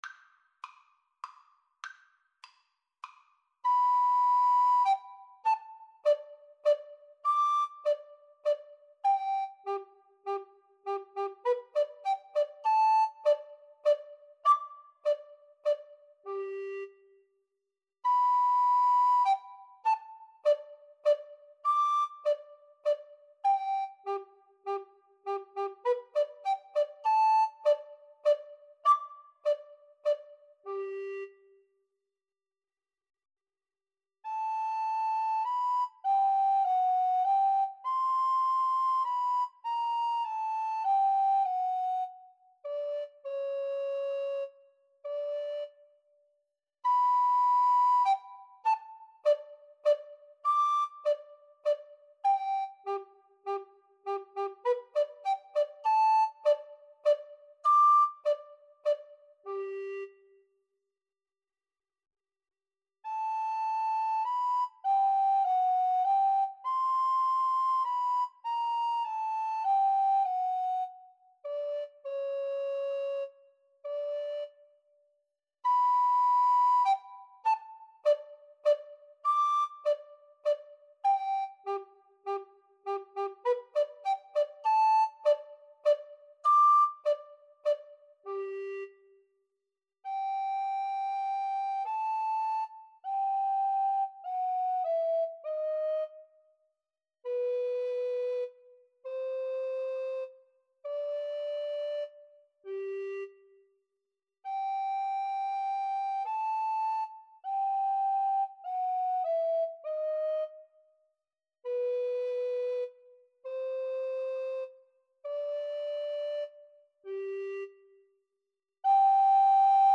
3/4 (View more 3/4 Music)
Allegretto - Menuetto
Classical (View more Classical Alto Recorder Duet Music)